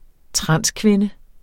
Udtale [ ˈtʁɑnˀs- ]